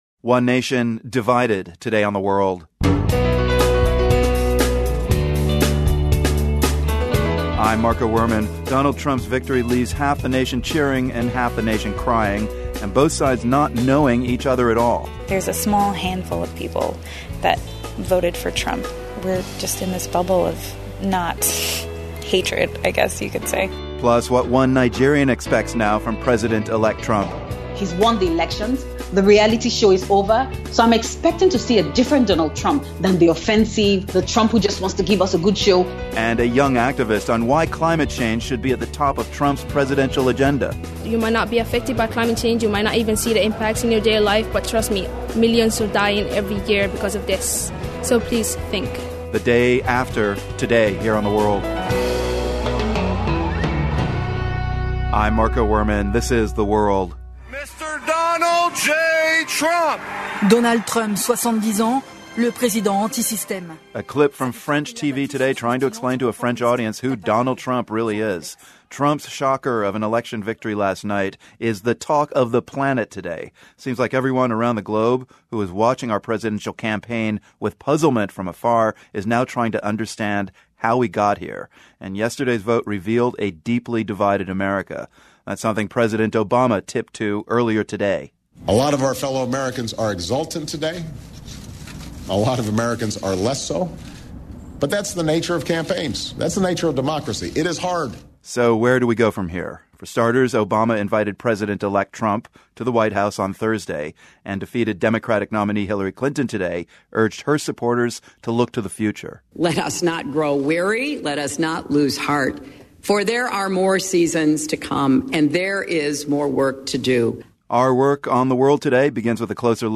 And, we head to Mexico for reaction there to Trump's win. Plus, women in different parts of the globe weigh in on what they think of the Trump election.